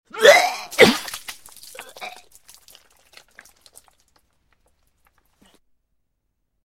vomit.wav